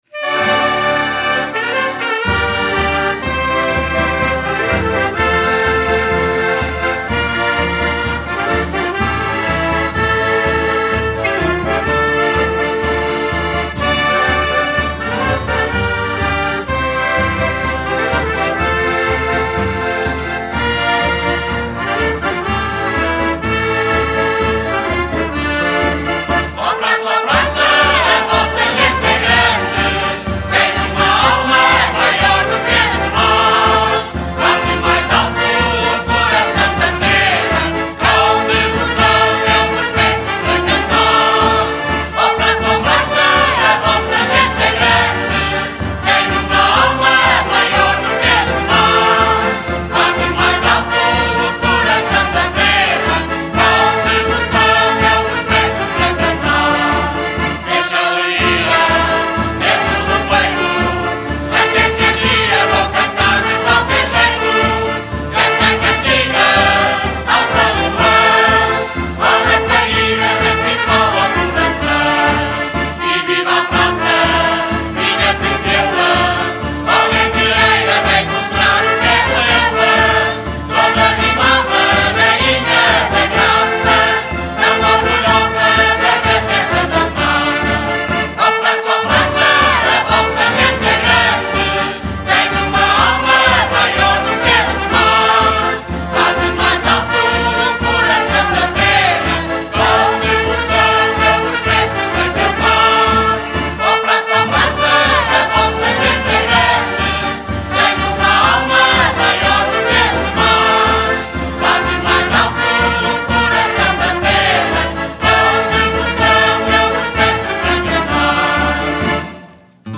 (Marcha)